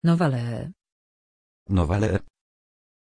Aussprache von Novalee
pronunciation-novalee-pl.mp3